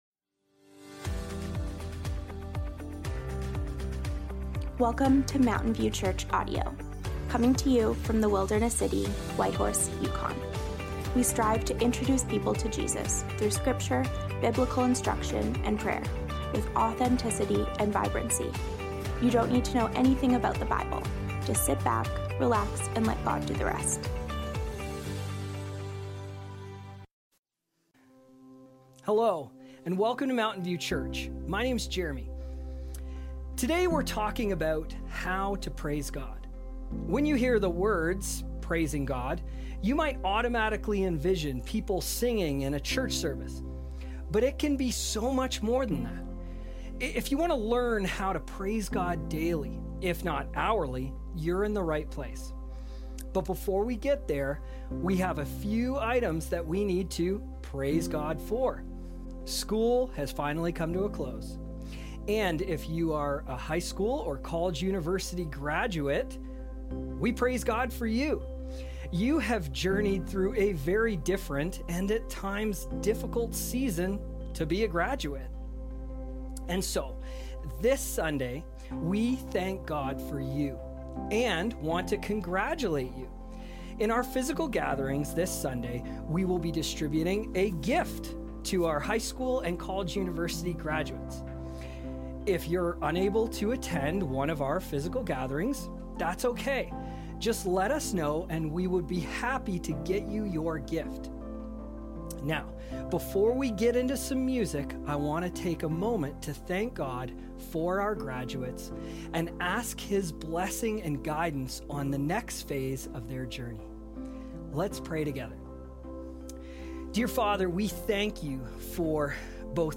How to Praise God? (Psalms, Ep. 11 - Sermon Audio)